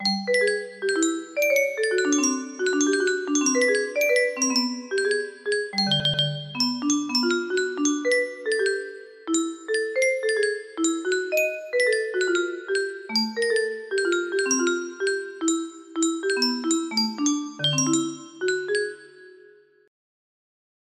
dnd fever dream music box melody